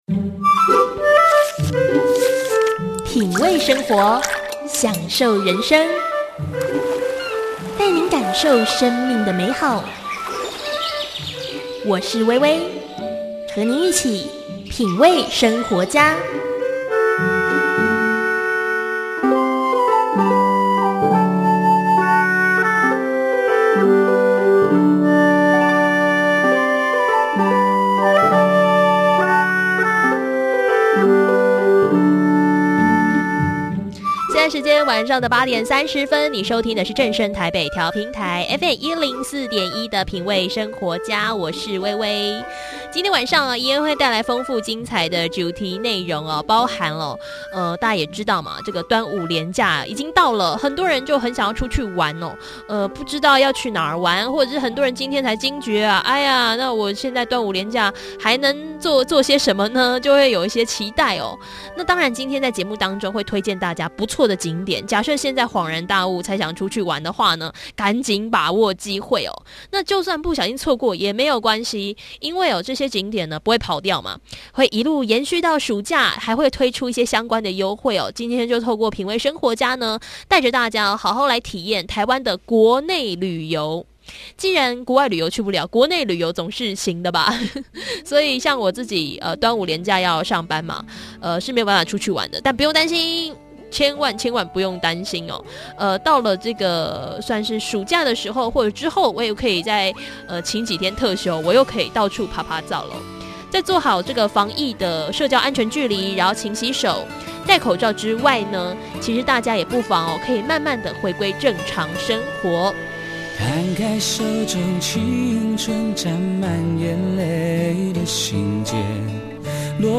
端午連假早就想好要去哪裡玩耍了，今天邀請到桃園市觀旅局的楊勝評局長分享台北近郊的桃園有什麼好玩的地方呢？喜歡戶外活動或是靜態文青景點的民眾都能好好享受桃園好風情喔，並且桃園市也祭出許多優惠方案，讓大家以優惠的價格住桃園、暢玩桃園！